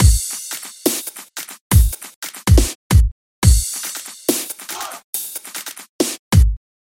裂纹鼓
描述：这是我在Fl.Dub/Glitchstep中制作的一个突兀的鼓。
标签： 140 bpm Dubstep Loops Drum Loops 1.16 MB wav Key : Unknown
声道立体声